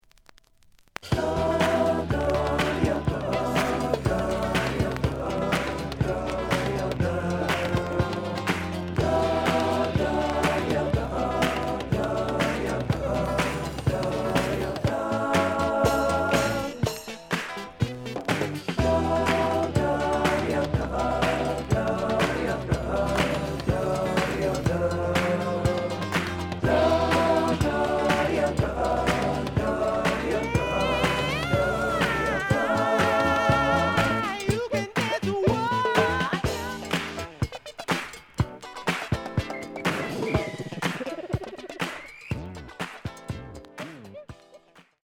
The audio sample is recorded from the actual item.
●Format: 7 inch
●Genre: Disco